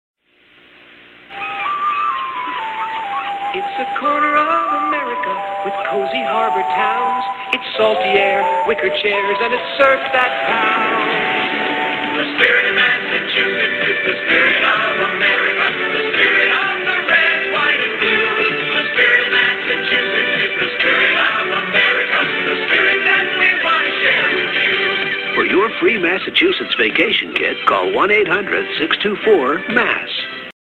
Spirit_of_Massachusetts_1980s_Commercial_Audio.mp3